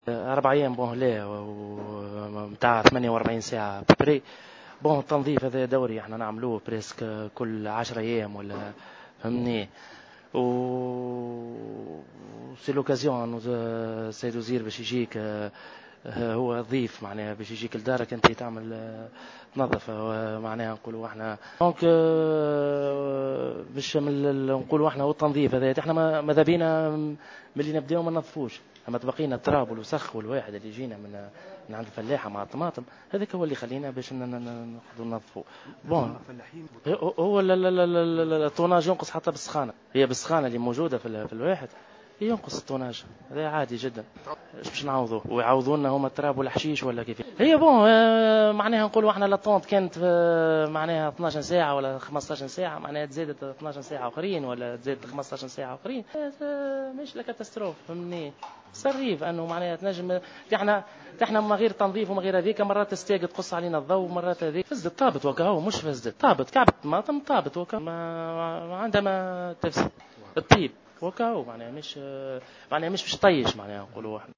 تصريحات التجار t√©l√©charger partager sur